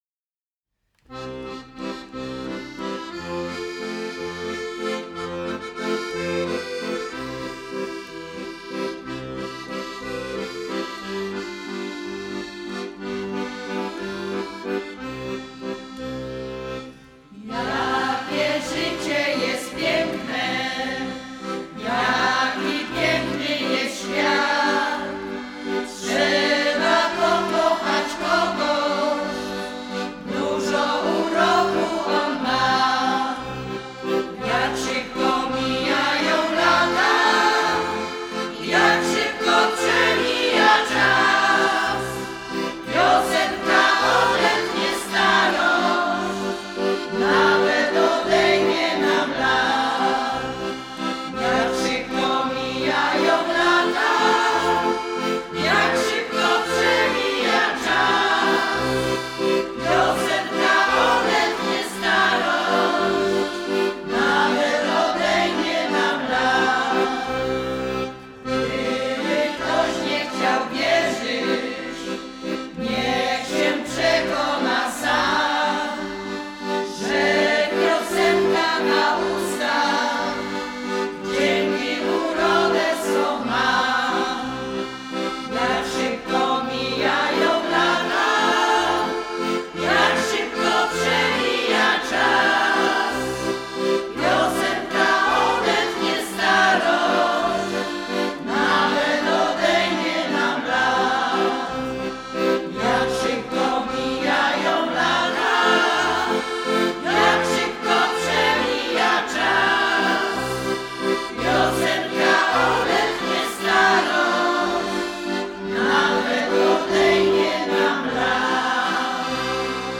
[PIOSENKA] Życie jest piękne.
Repertuar zespołu śpiewaczego „Roczynianki” został nagrany w ramach projektu: "Archiwum Gminy Andrychów.
akordeon